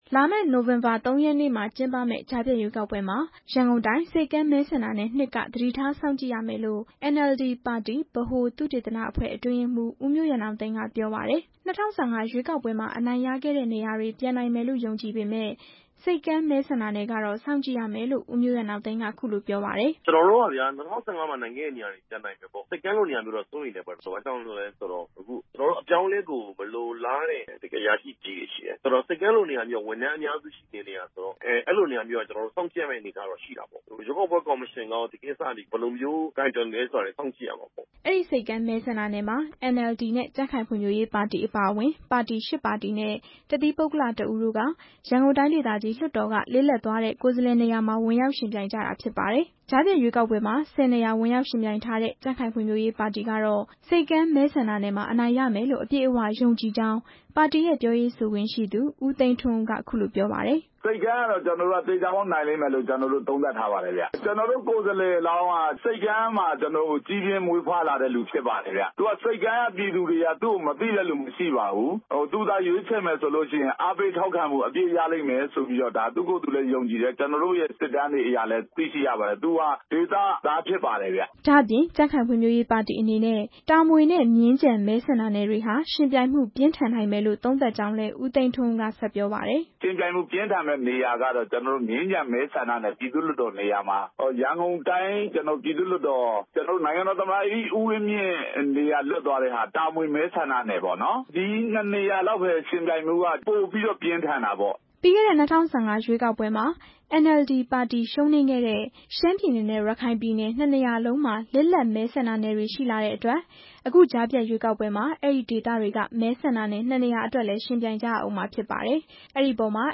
ကြားဖြတ်ရွေးကောက်ပွဲအခြေအနေ စုစည်းမေးမြန်းချက်